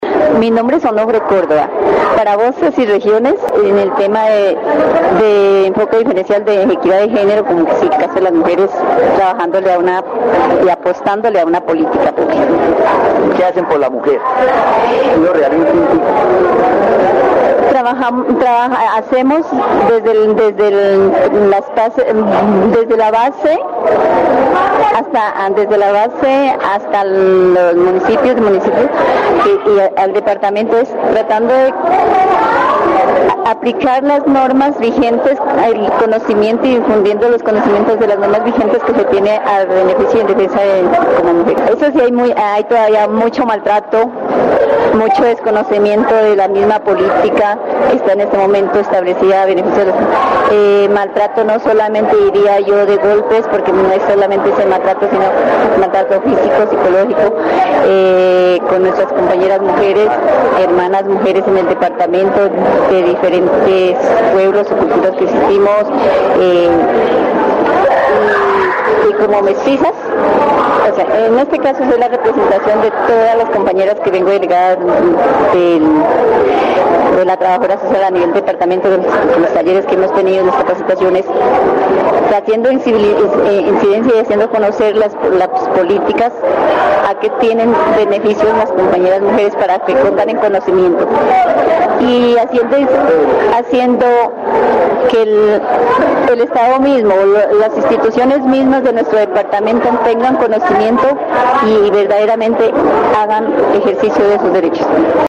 Entrevista sobre el maltrato y el desconocimiento de las políticas públicas en Vichada, con un enfoque en la educación y la aplicación de normas para el empoderamiento de las mujeres.
Vichada (Región, Colombia) -- Grabaciones sonoras , Programas de radio , Maltrato a la mujer , Desconocimiento de políticas